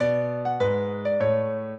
piano
minuet5-9.wav